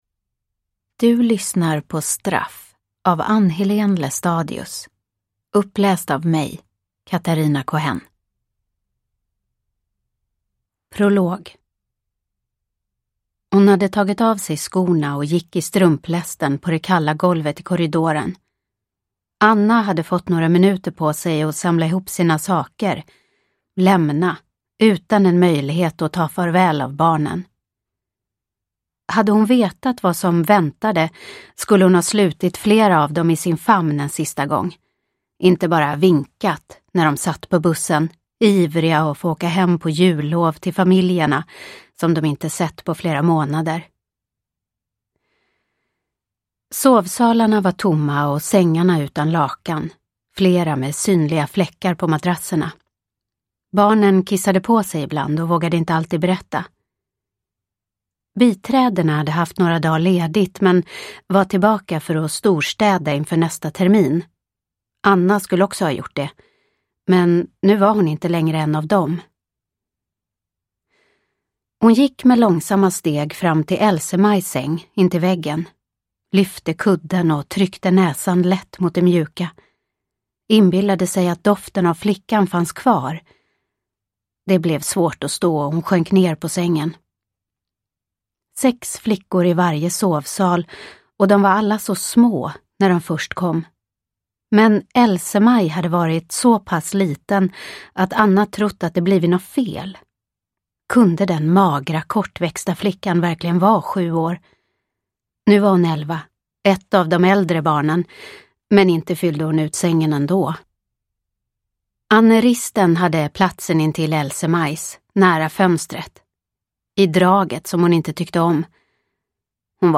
Straff – Ljudbok – Laddas ner